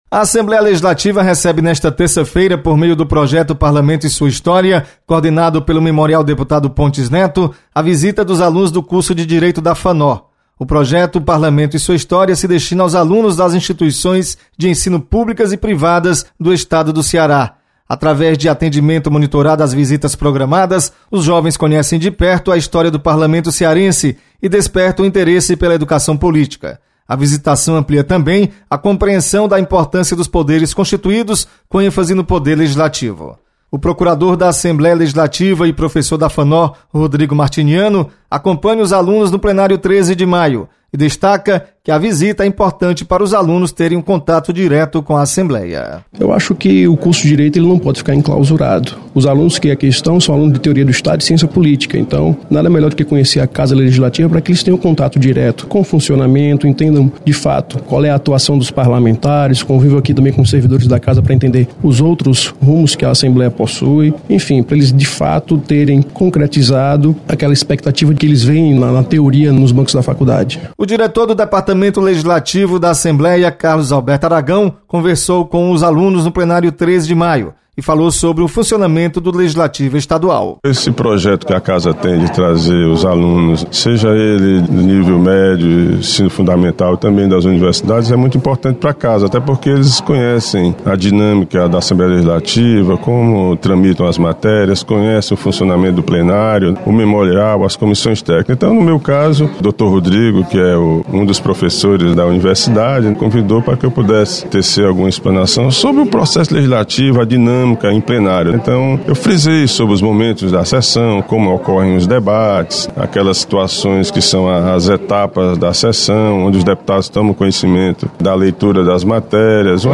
Memorial Pontes Neto recebe alunos do Curso de Direito da Fanor. Repórter